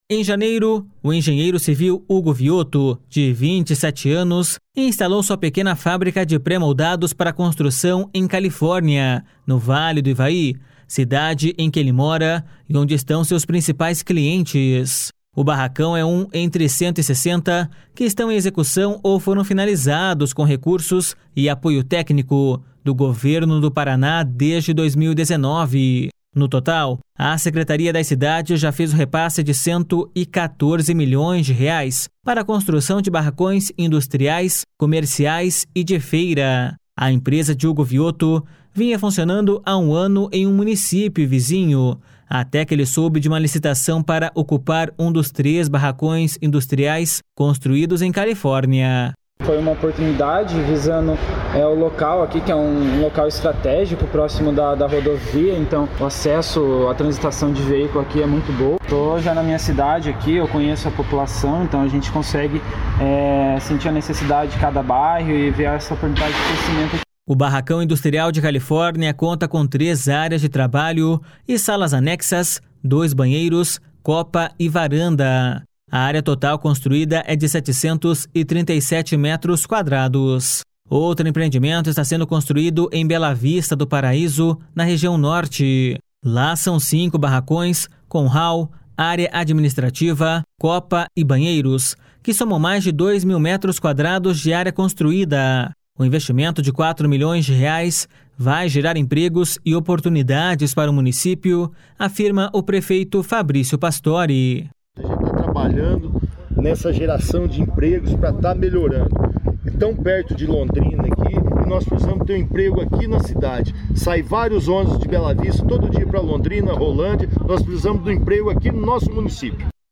No momento, outros 66 milhões de reais em investimento estão em processo de análise ou sendo licitados, lembra o secretário das Cidades, Eduardo Pimentel.// SONORA EDUARDO PIMENTEL.//
Repórter